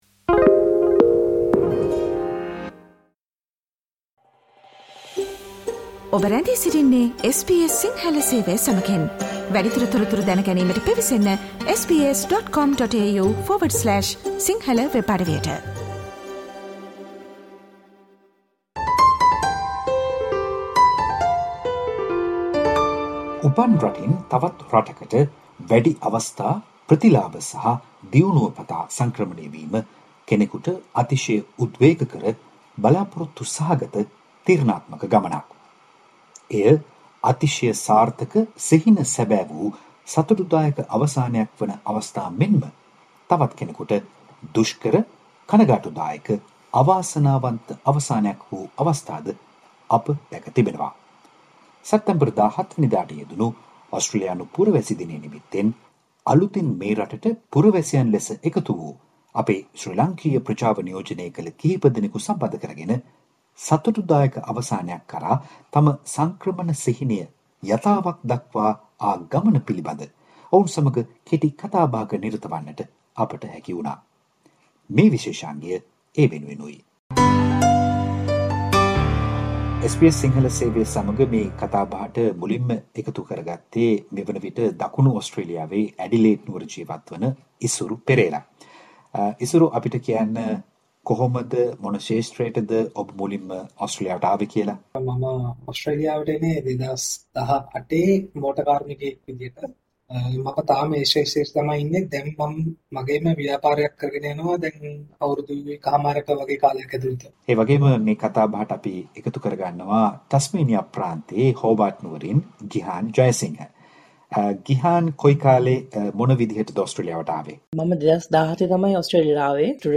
උපතින් ලැබූ පුරවැසිභාවය වෙනුවට තෝරාගැනීමෙන් පුරවැසිභාවයට ලබාගැනීම අභියෝගාත්මක, දුෂ්කර හා තීරණාත්මක මෙන්ම සංවේදී ගමනක්. ඒ ගමන සාර්ථකව අවසන් කළ තිදෙනෙකු සමඟ කළ මේ කතාබහට සවන්දෙන්න.